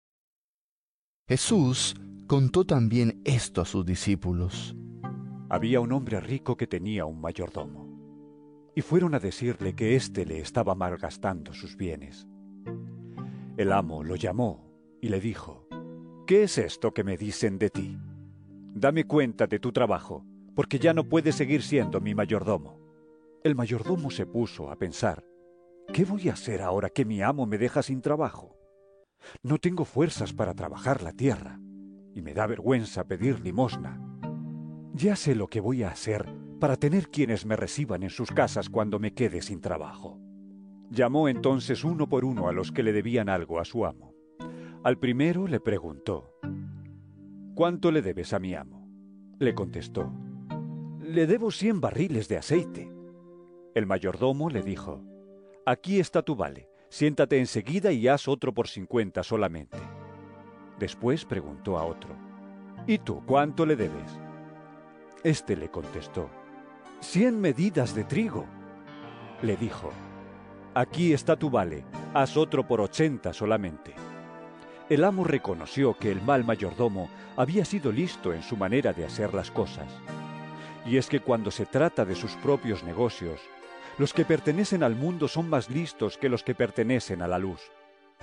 Lc 16 1-8 EVANGELIO EN AUDIO